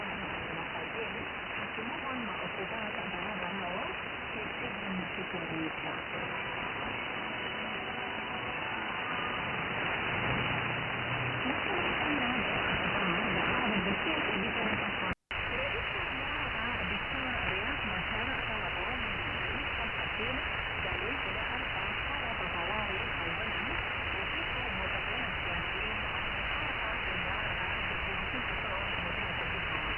For the comparison I made audio recordings of the two receivers.
Second 0-15 >> SDRplay RSPduo
Second 15-30 >> Winradio G33DDC Excalibur Pro
Timesignal